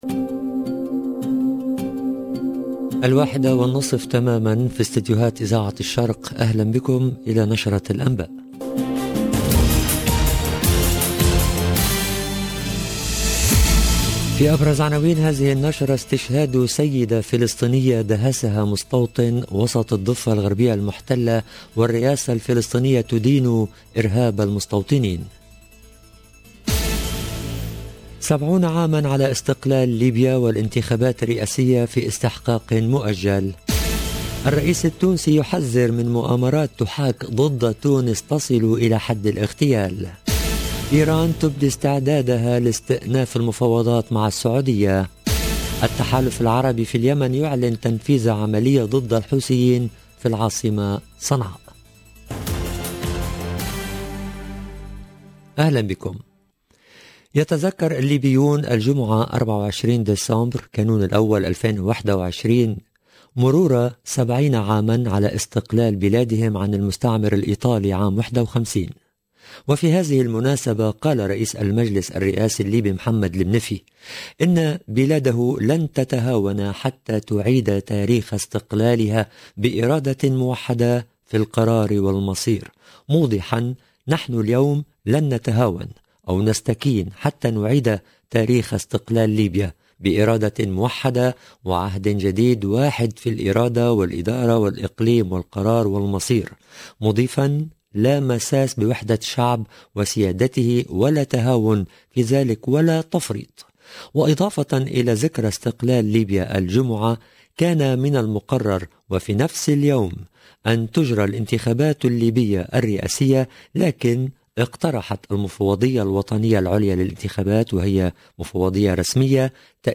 LE JOURNAL DE 13H30 EN LANGUE ARABE DU 24/12/2021
EDITION DU JOURNAL EN LANGUE ARABE DE 13H30 DU 24/12/2021